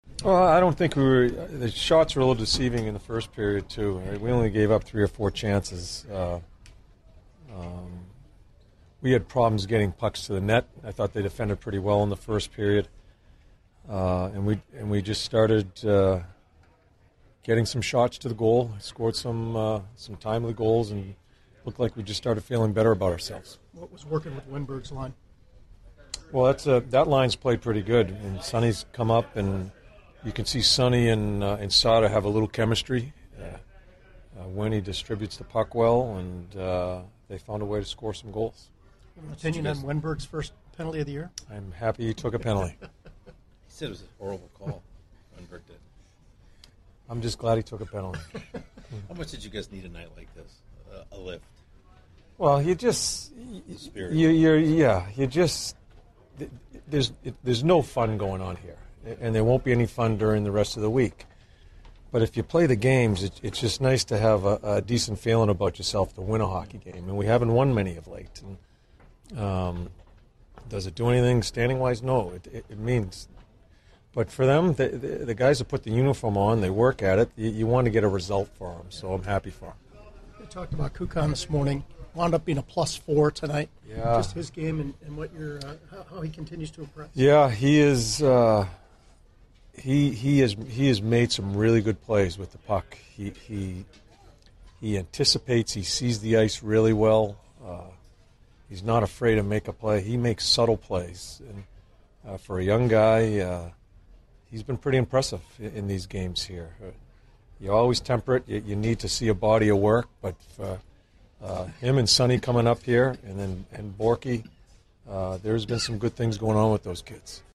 An episode by CBJ Interviews